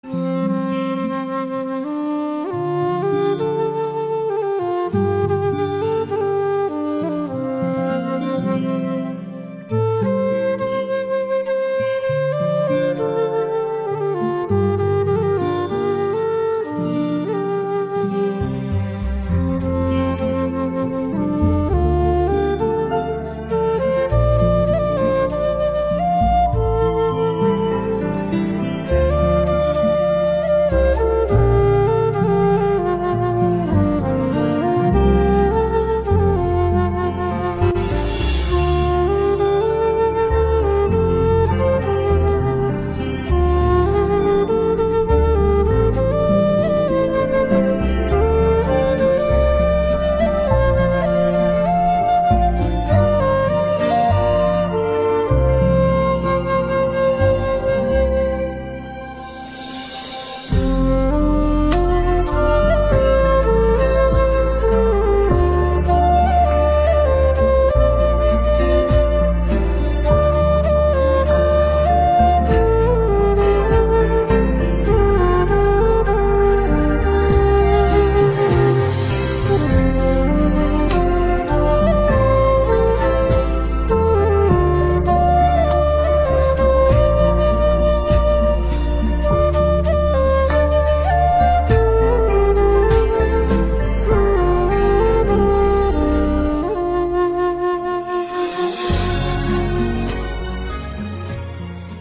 Giai điệu tiêu sầu, trầm mặc và đầy cảm xúc.
Nét đặc sắc của âm thanh Nam Tiêu